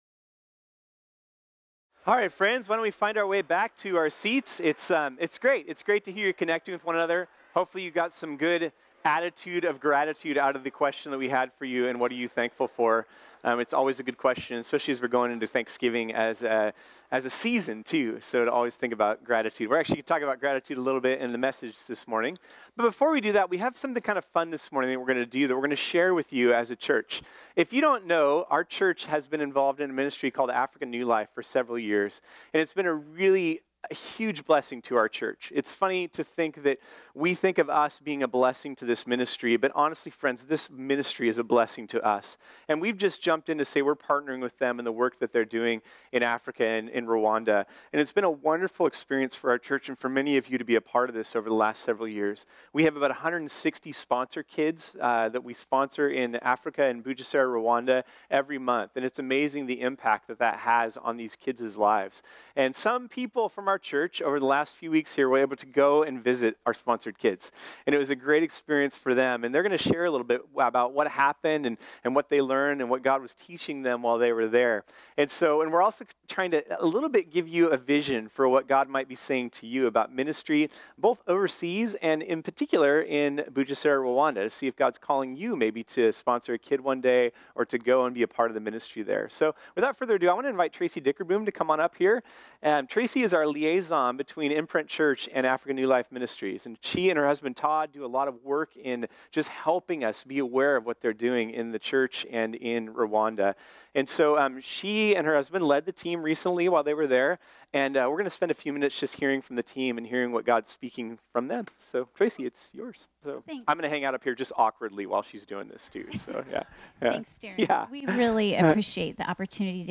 This sermon was originally preached on Sunday, November 11, 2018.